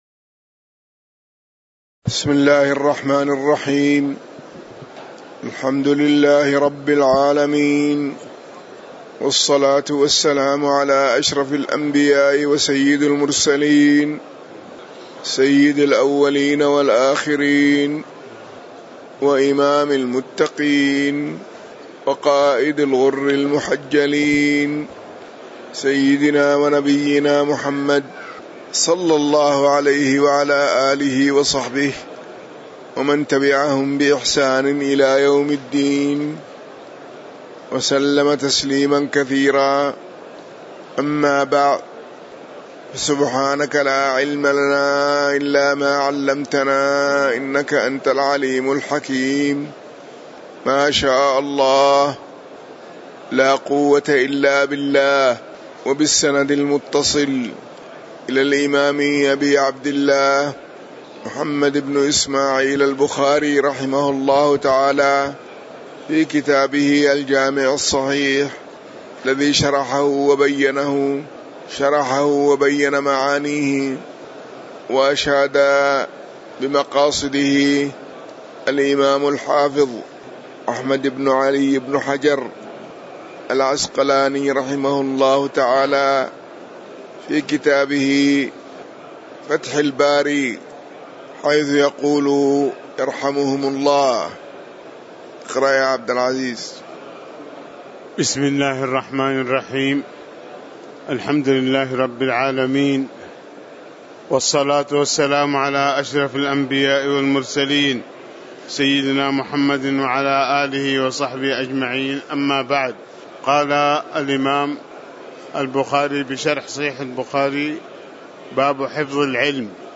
تاريخ النشر ١٣ رمضان ١٤٣٩ هـ المكان: المسجد النبوي الشيخ